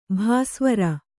♪ bhāsvara